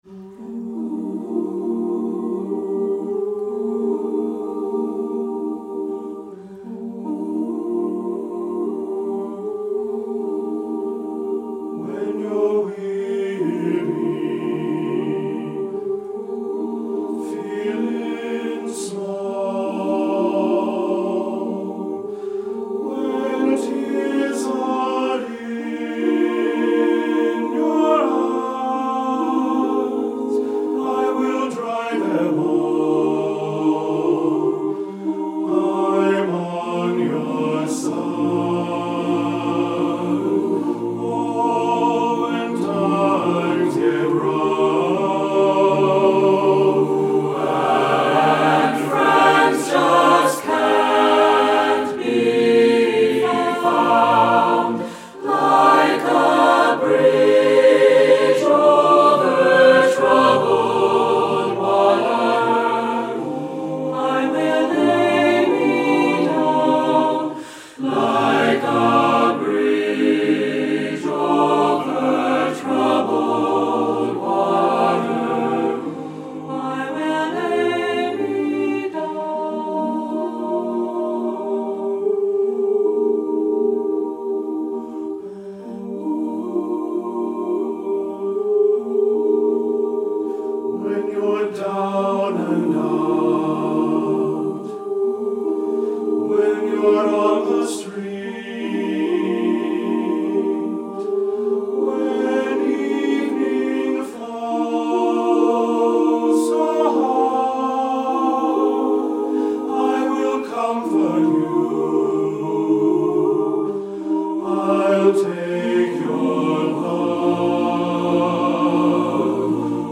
Voicing: SATTBB a cappella